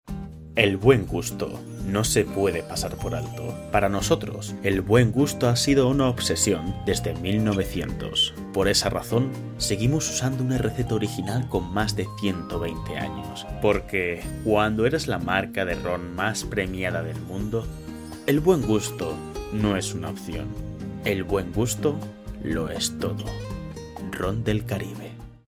Demo publicidad
Castellano neutro
Young Adult
Cuña.MP3